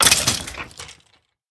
Minecraft Version Minecraft Version 25w18a Latest Release | Latest Snapshot 25w18a / assets / minecraft / sounds / mob / skeleton / death.ogg Compare With Compare With Latest Release | Latest Snapshot
death.ogg